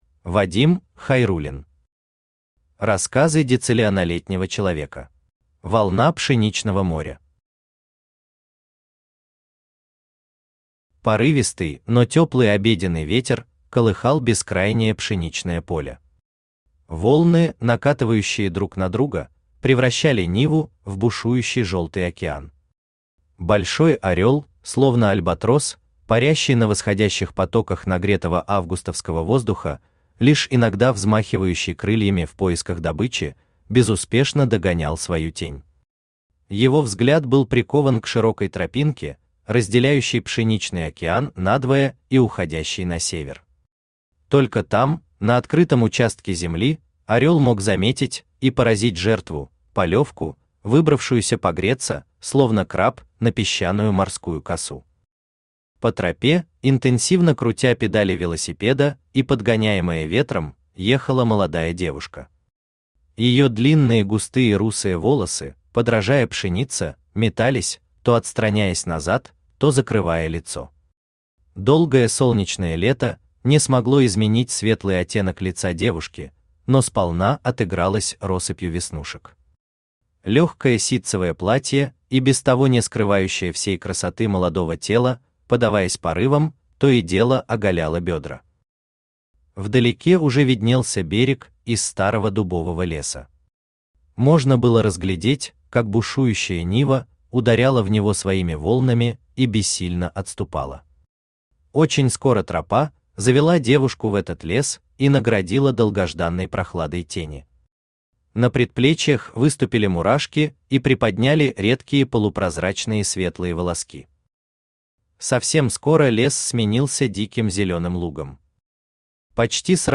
Аудиокнига Рассказы дециллионолетнего человека | Библиотека аудиокниг
Aудиокнига Рассказы дециллионолетнего человека Автор Вадим Хайруллин Читает аудиокнигу Авточтец ЛитРес.